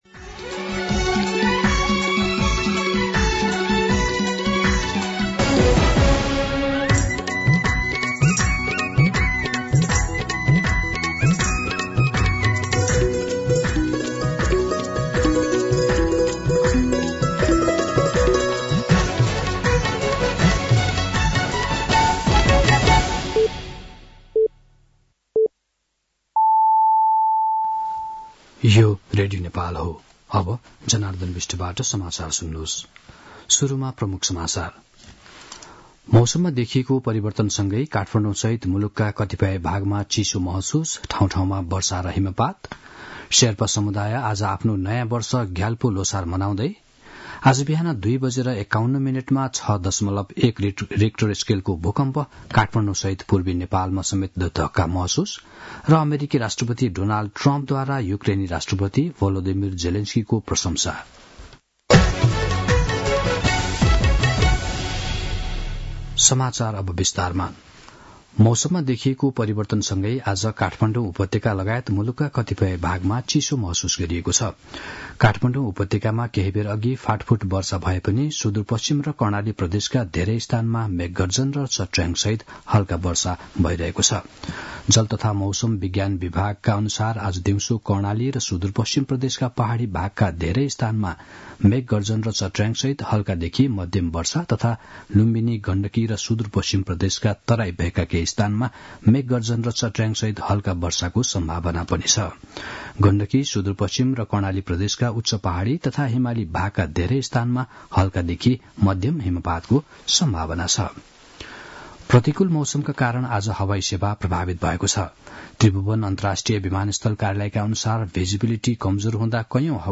दिउँसो ३ बजेको नेपाली समाचार : १७ फागुन , २०८१
3-pm-Nepali-News-3.mp3